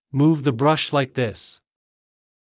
ﾑｰﾌﾞ ｻﾞ ﾌﾞﾗｯｼ ﾗｲｸ ﾃﾞｨｽ